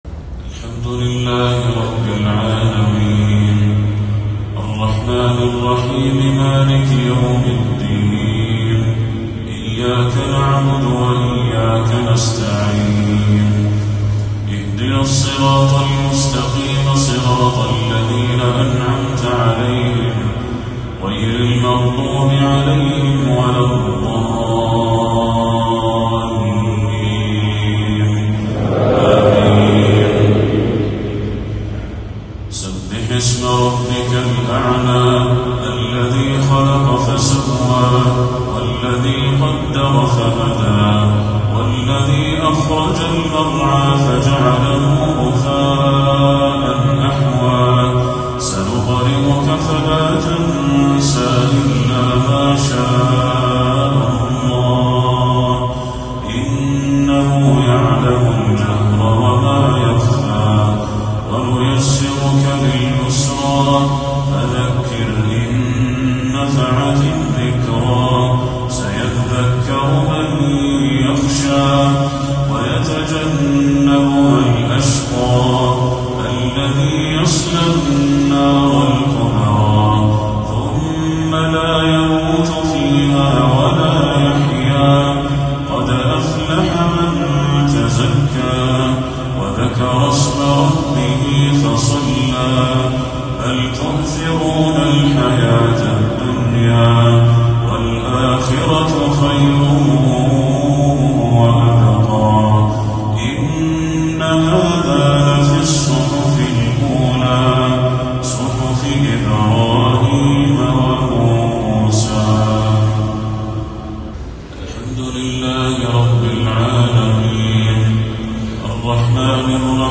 تلاوة في غاية الجمال من سورتيّ الأعلى والغاشية للشيخ بدر التركي | صلاة الجمعة 26 صفر 1446هـ > 1446هـ > تلاوات الشيخ بدر التركي > المزيد - تلاوات الحرمين